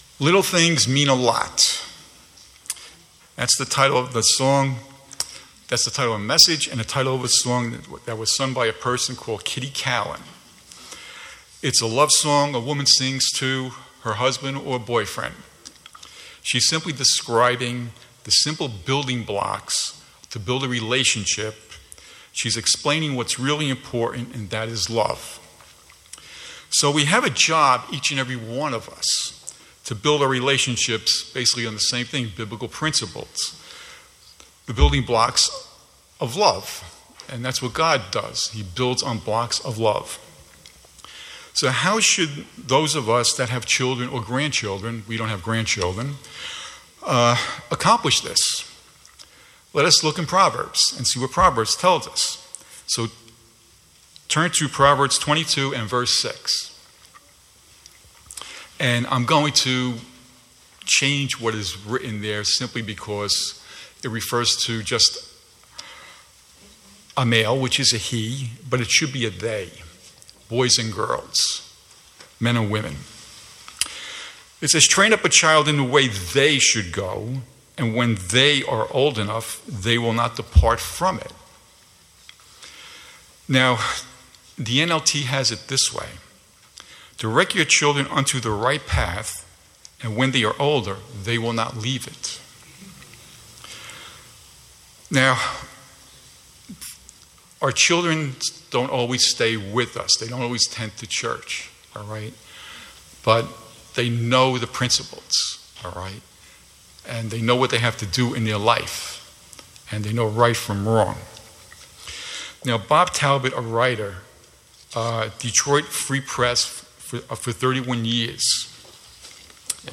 Sermons
Given in New York City, NY